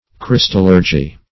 Meaning of crystallurgy. crystallurgy synonyms, pronunciation, spelling and more from Free Dictionary.
crystallurgy.mp3